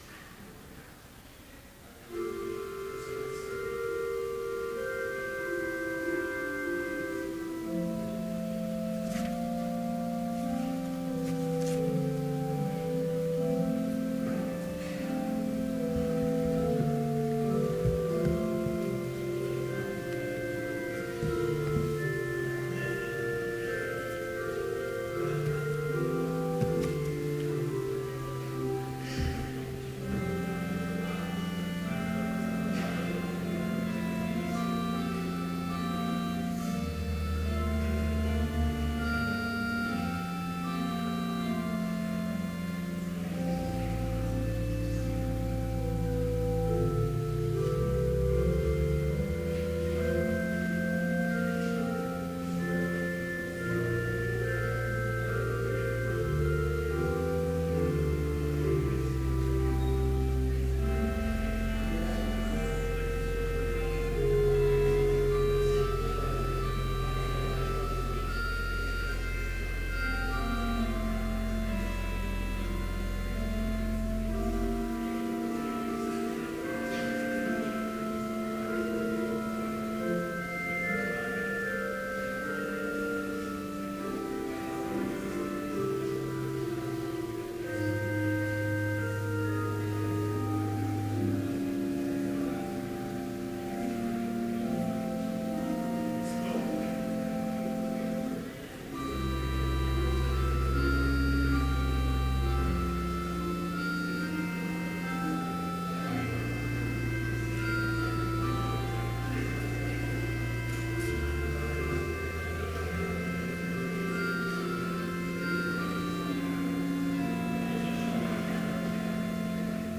Complete service audio for Chapel - March 18, 2015